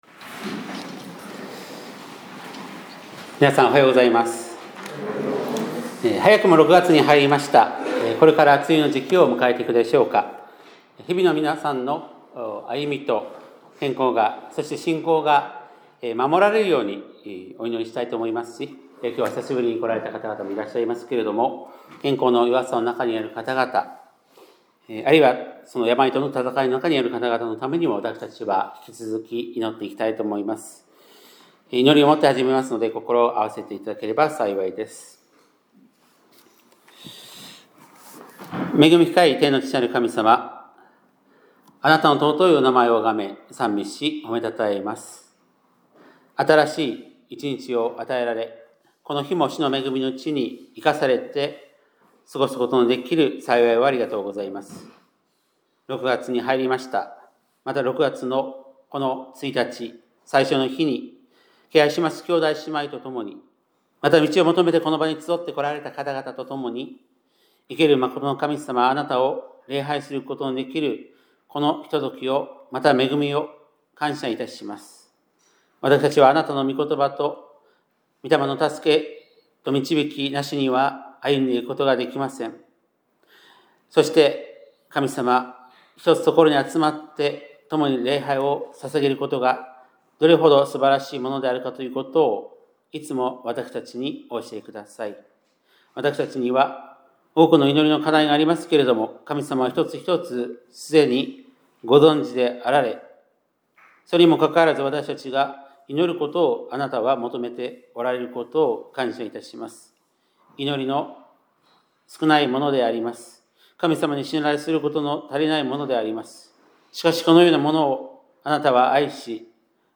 2025年6月1日（日）礼拝メッセージ